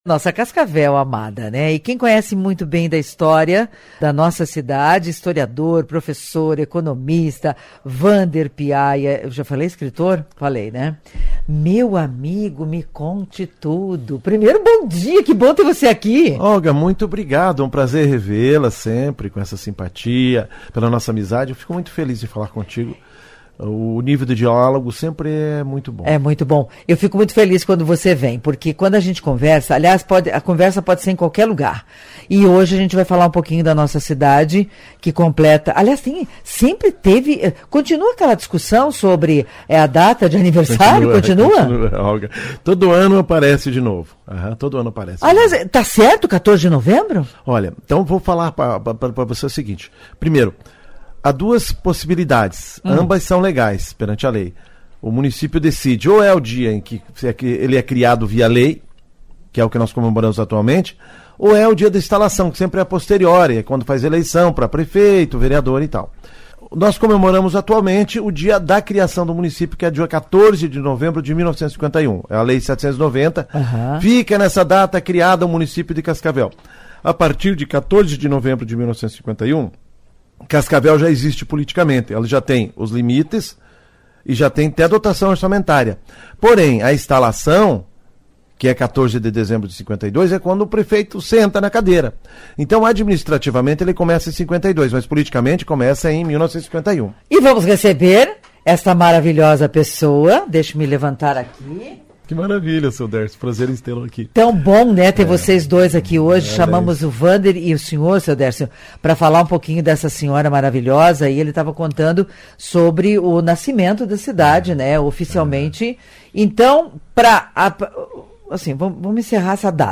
Em entrevista ao Revista CBN